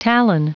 Prononciation du mot talon en anglais (fichier audio)
Prononciation du mot : talon